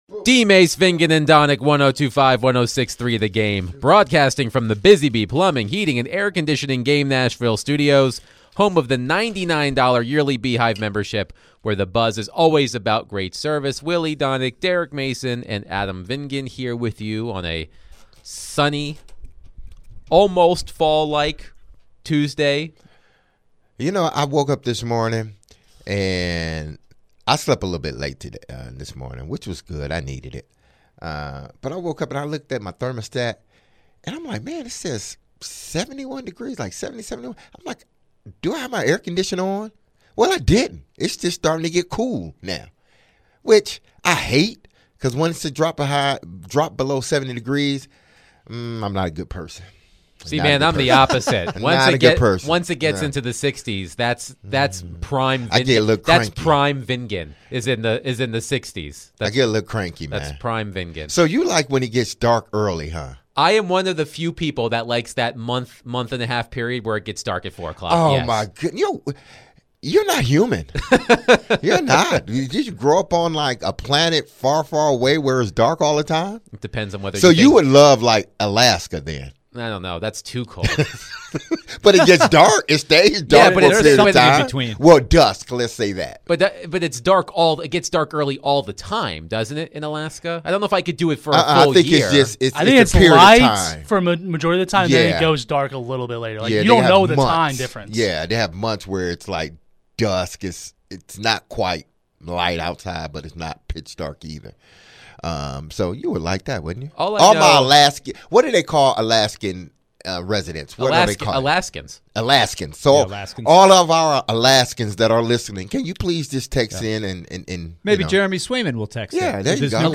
Will Will Levis be good to go against the Colts on Sunday? Later in the hour, ESPN NFL Analyst Tim Hasselbeck joined the show to talk about the Titans' matchup with the Colts and the Jets firing Robert Saleh.